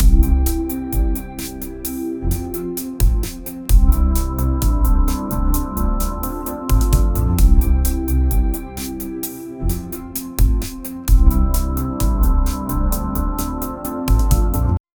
The background music is soft.